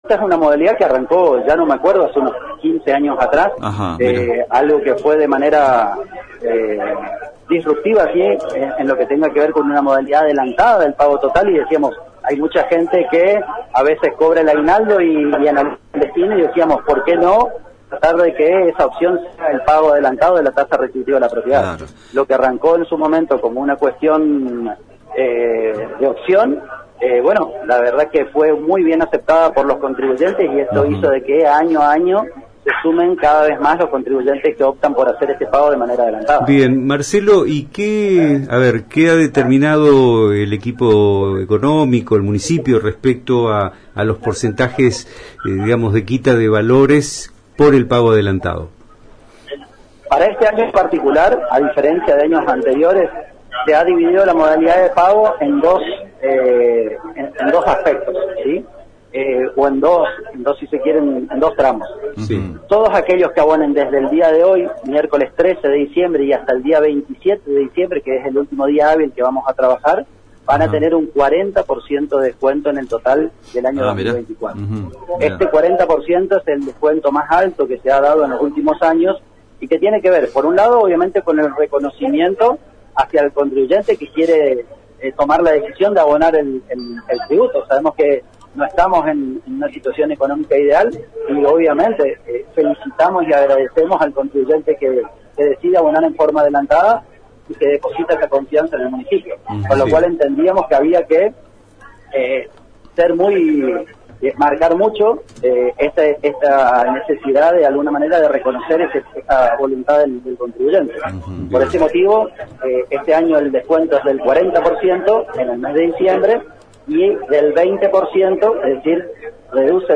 El secretario de Hacienda de la Municipalidad de Eldorado, C.P. Marcelo Mikulán, en diálogo con ANG y Multimedios Génesis se refirió a esta iniciativa como una oportunidad para que los vecinos puedan mantener sus impuestos al día y al mismo tiempo acceder a importantes descuentos y al sorteo de importantes premios como reconocimiento a la voluntad del contribuyente que decide abonar el tributo pese a la situación económica actual.